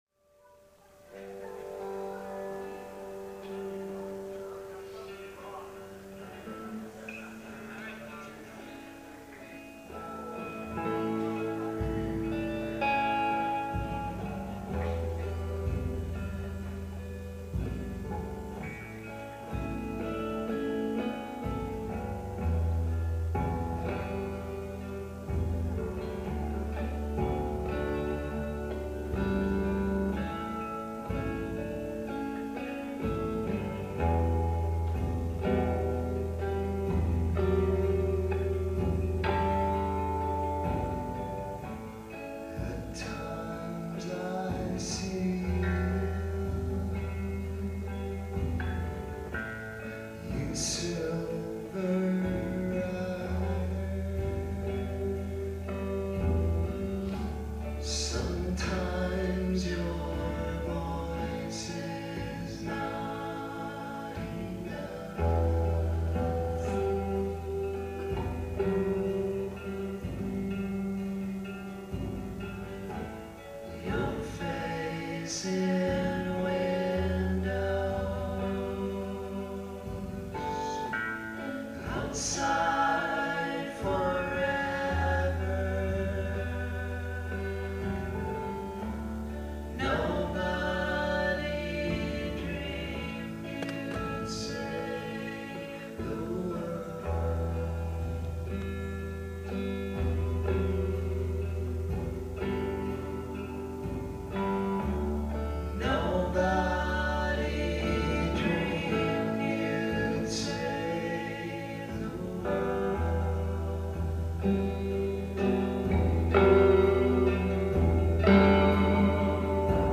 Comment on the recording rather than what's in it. Live at the Somerville Theater, Somerville, MA